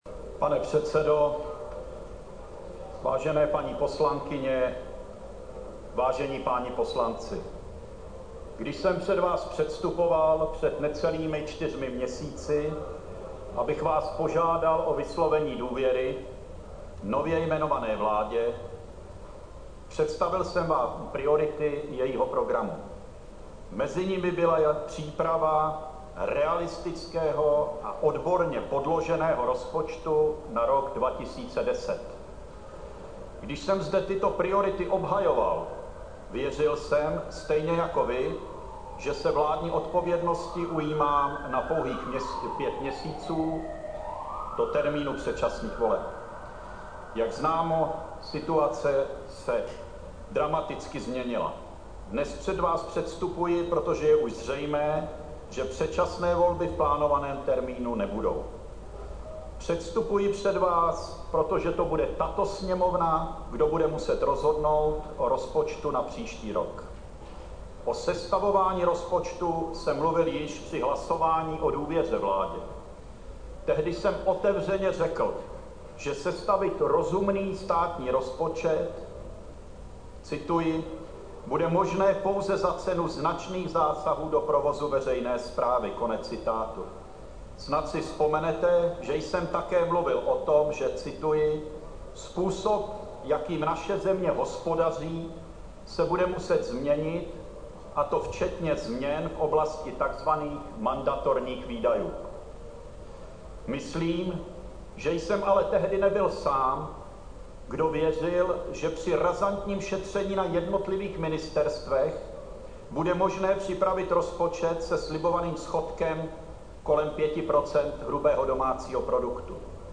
Úvodní projev předsedy vlády Jana Fischera v Poslanecké sněmovně před projednáváním návrhu úsporných opatření souvisejících s rozpočtem na rok 2010.